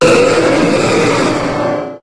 Cri de Méga-Rayquaza dans Pokémon HOME.